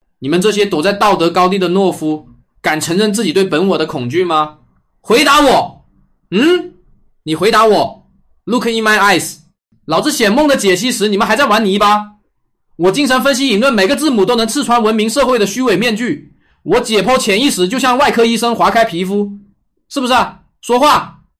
Breaking Down Mandarin_ZeroShot.mp3